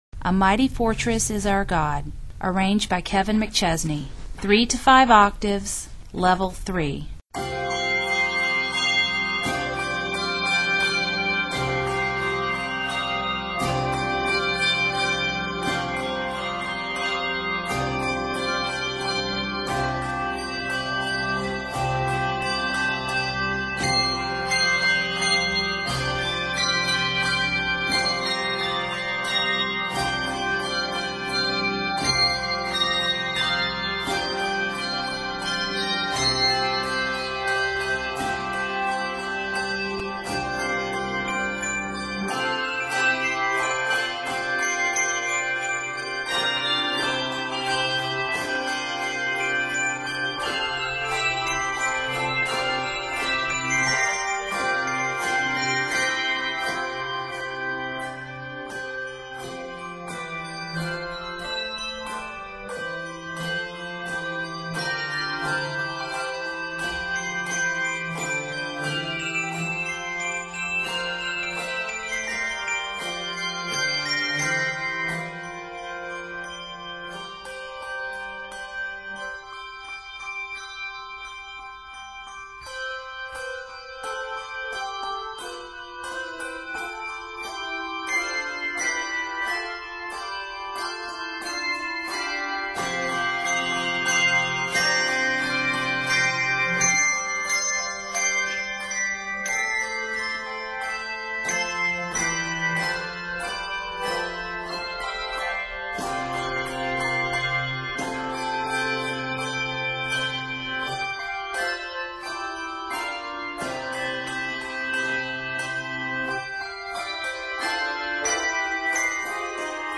big and bold arrangement